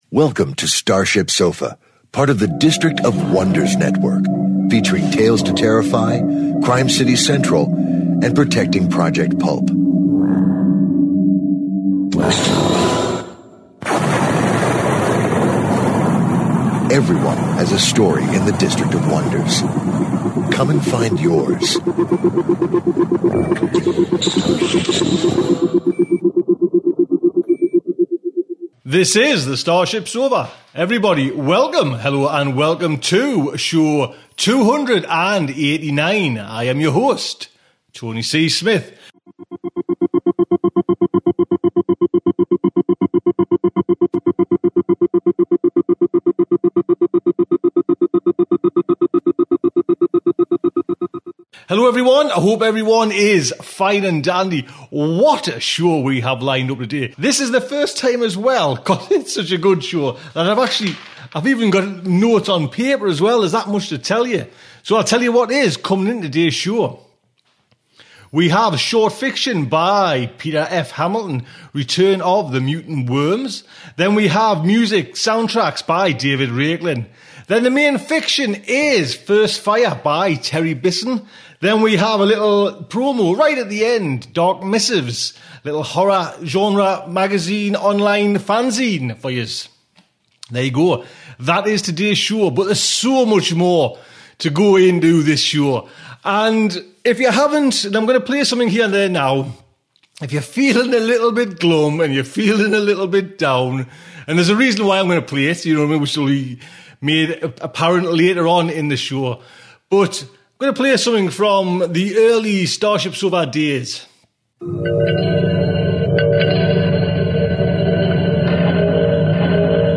Short Fiction